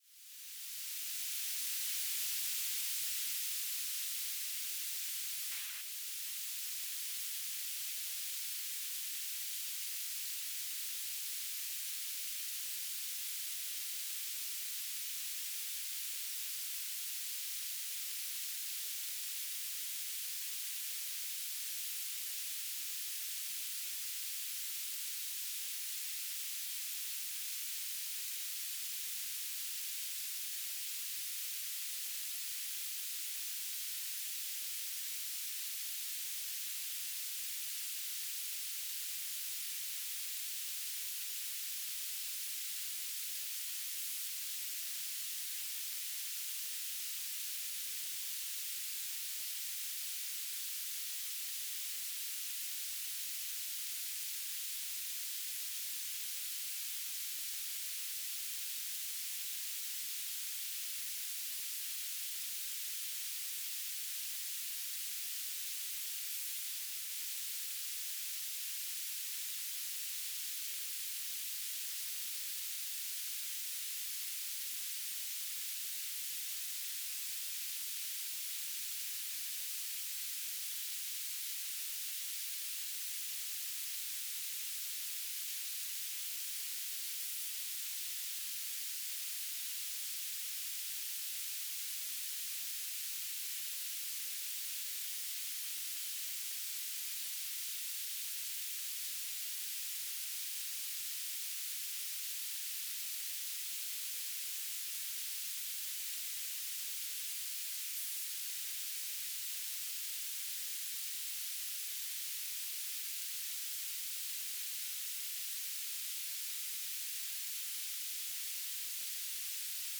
"transmitter_description": "Mode U - BPSK9k6 G3RUH - Beacon",
"transmitter_mode": "BPSK",